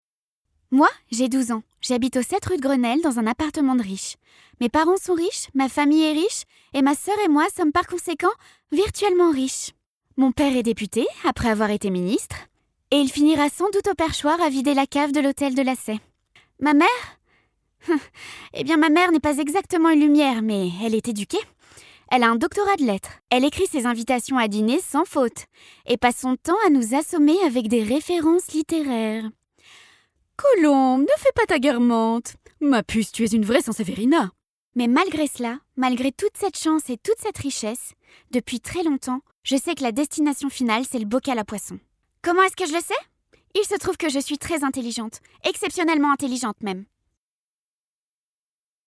Voix off
Narration
5 - 32 ans - Soprano